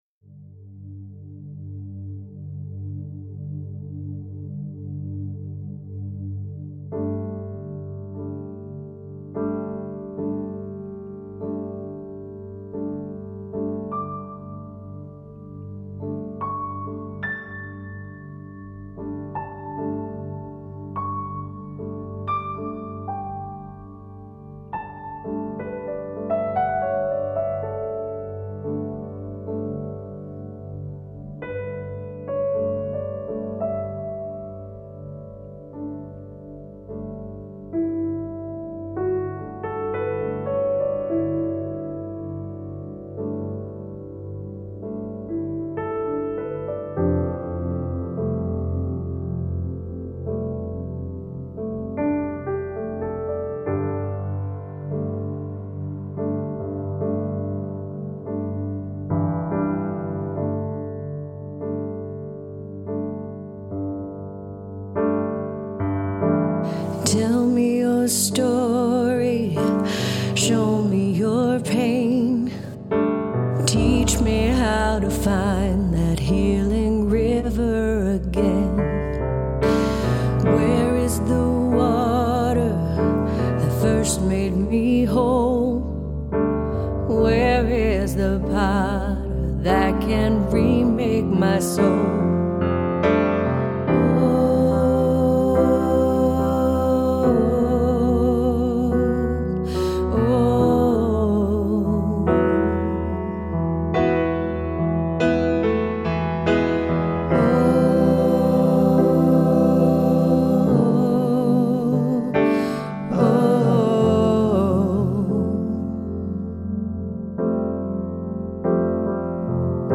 The heartbreaking vocal